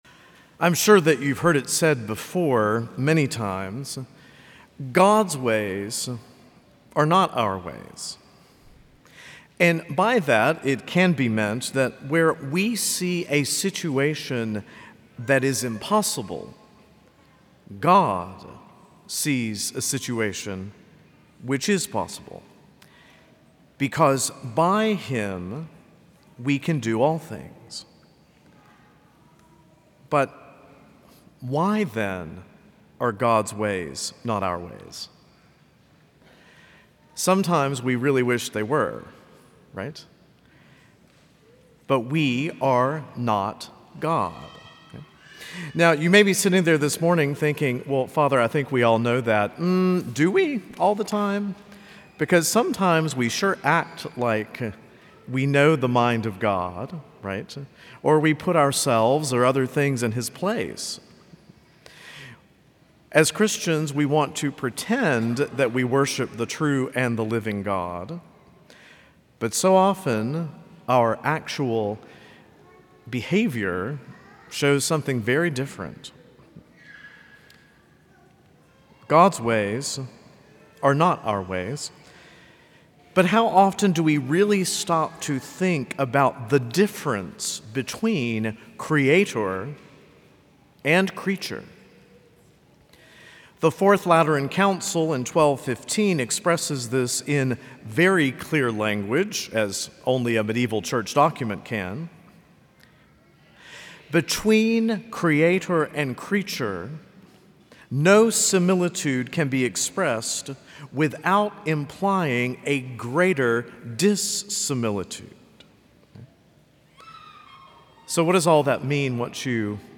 Homilies - Prince of Peace Catholic Church & School